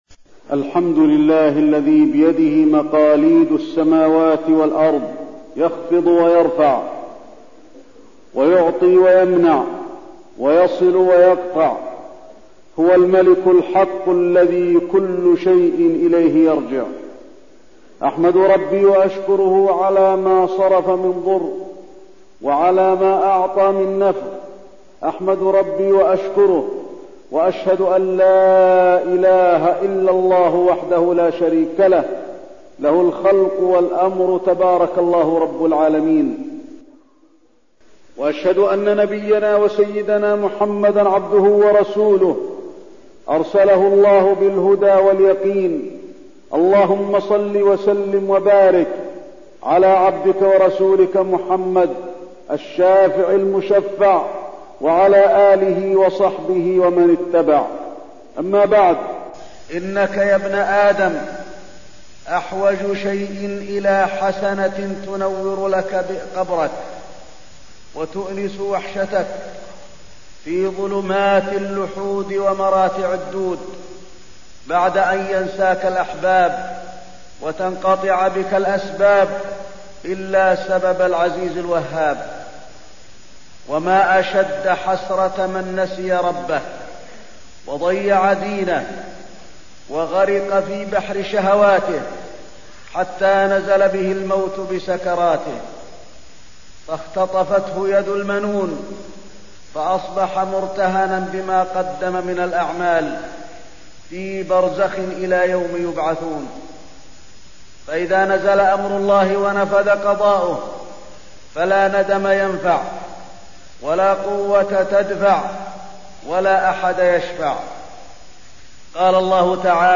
تاريخ النشر ١٨ رمضان ١٤١٨ هـ المكان: المسجد النبوي الشيخ: فضيلة الشيخ د. علي بن عبدالرحمن الحذيفي فضيلة الشيخ د. علي بن عبدالرحمن الحذيفي شهر رمضان والأحوال في الجزائر